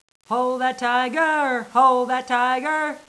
tiger.wav